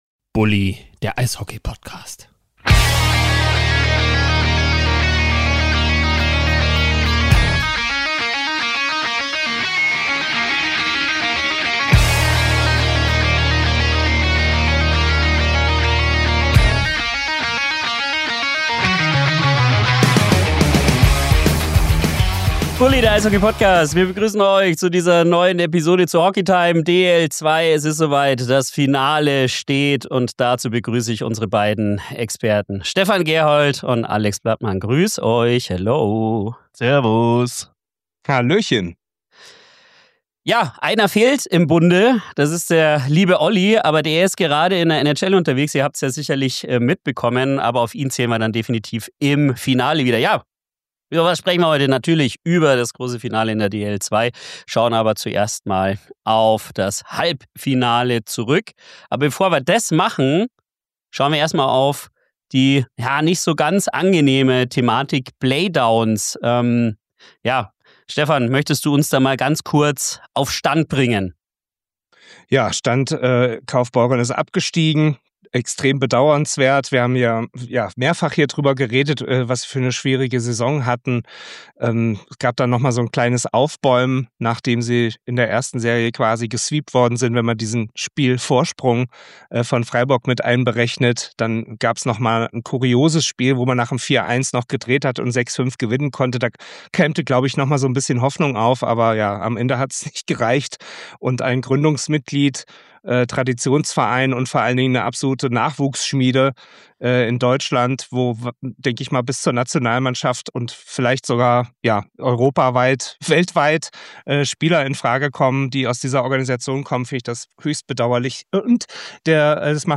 Mit fundierten Einschätzungen, persönlichen Meinungen und klaren Prognosen entsteht eine lebendige Diskussion rund um die wichtigsten Entwicklungen der Liga.